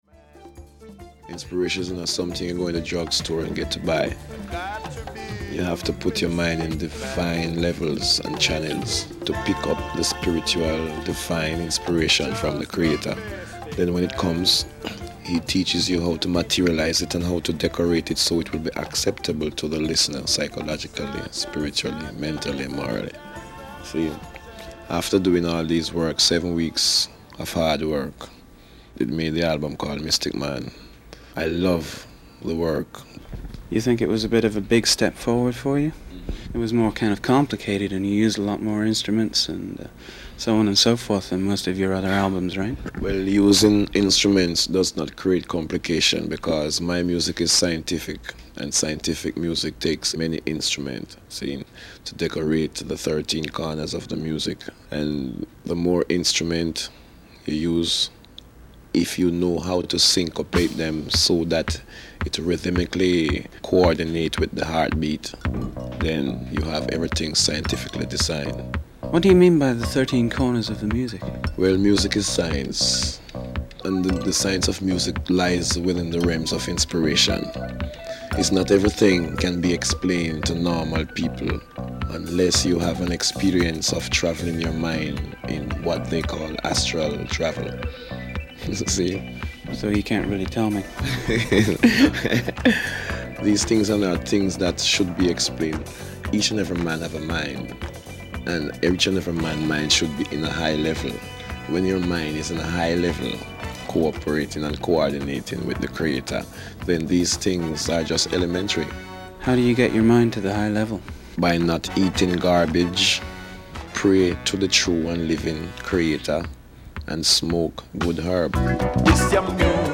The interview was conducted in September 1979 in New York Shitty during the Mystic Man tour.